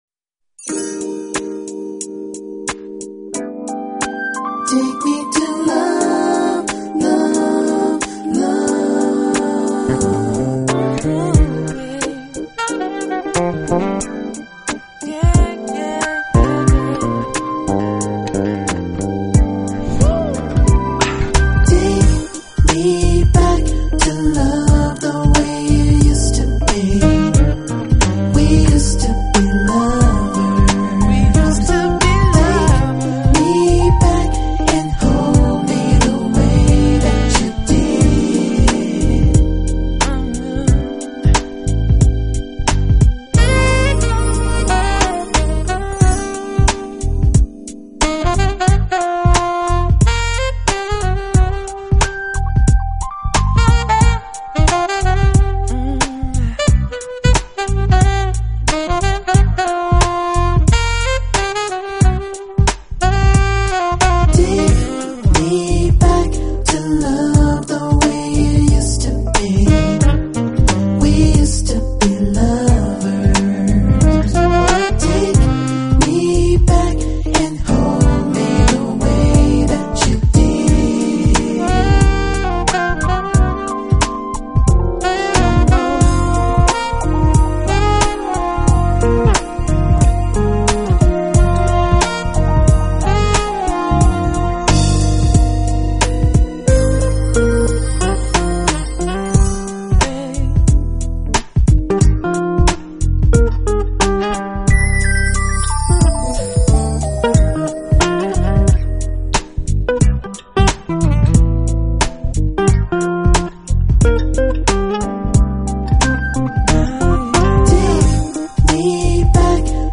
音乐风格：Smooth Jazz, R&B